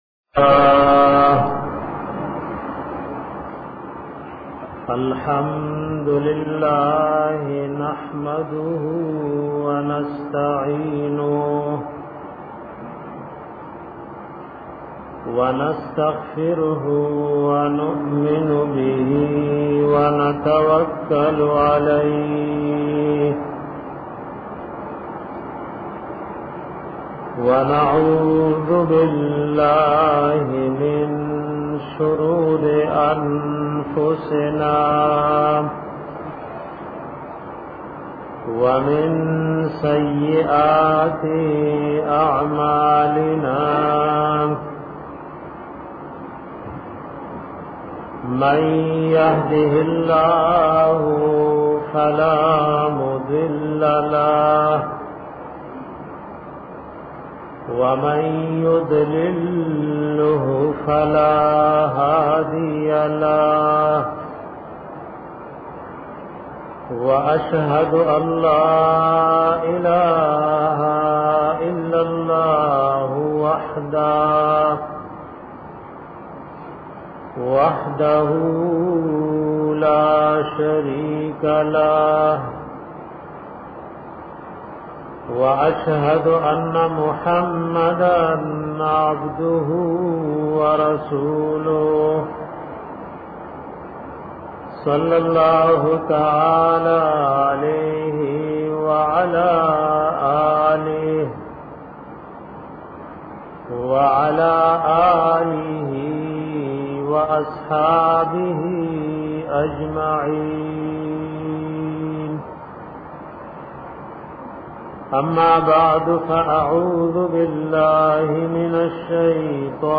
bayan pa bara da afwa apo saba kolo ka